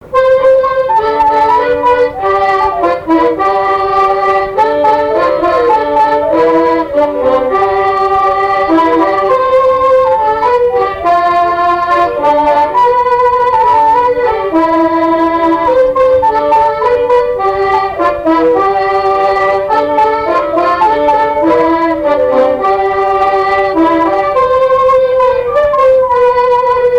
danse : marche
Pièce musicale inédite